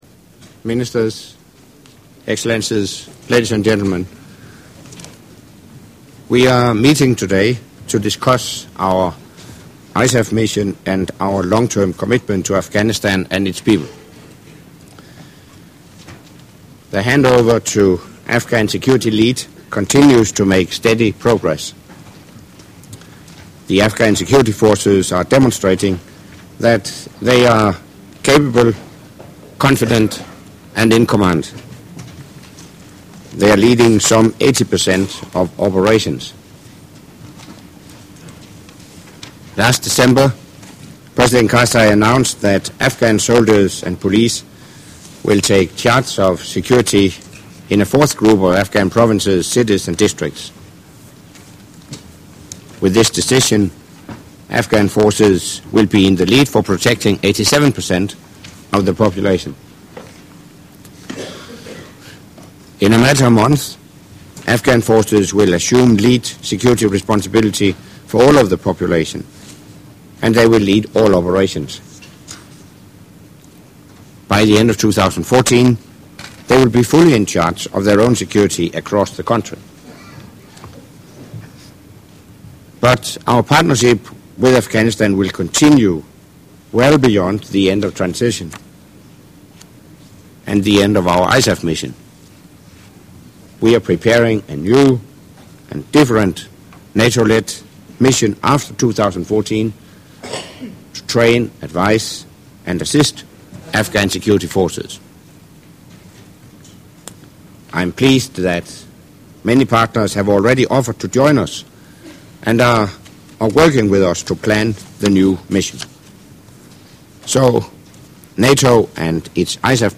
Opening remarks by NATO Secretary General Anders Fogh Rasmussen at the meeting of NATO Defence Ministers with non-NATO ISAF Contributing Nations